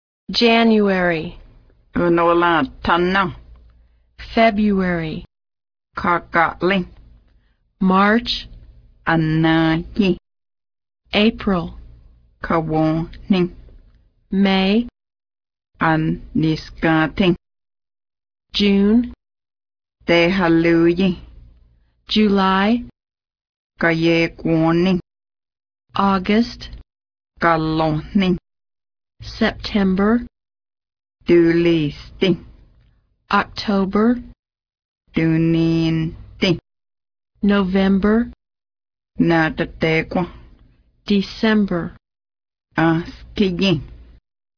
Cherokee Audio Lessons